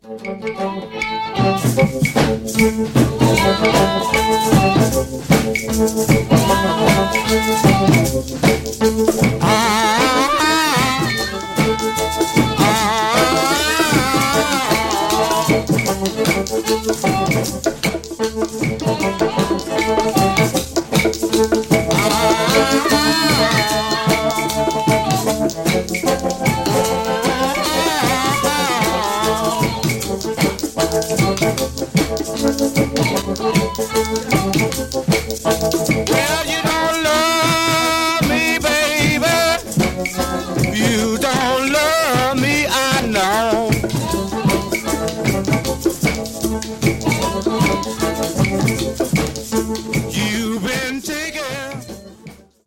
reverb guitar
D harp